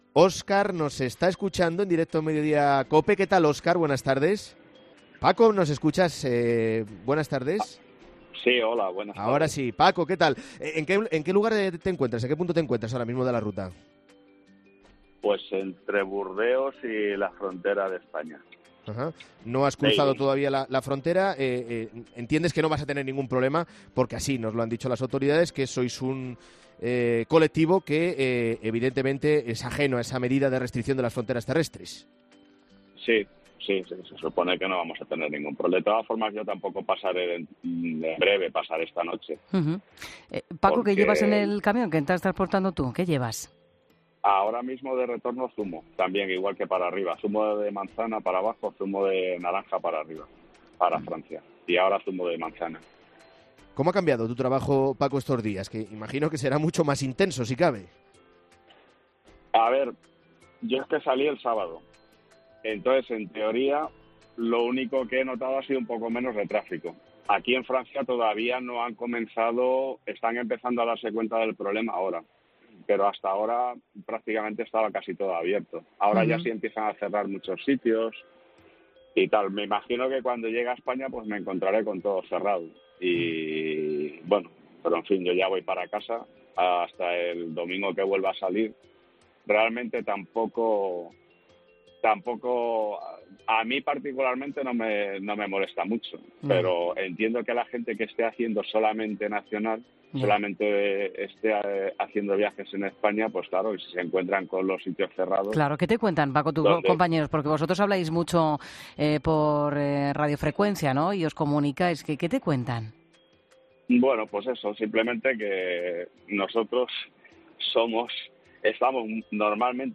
En Mediodía COPE hemos hablado con un transportista para que nos cuente cómo les afecta a ellos la crisis del coronavirus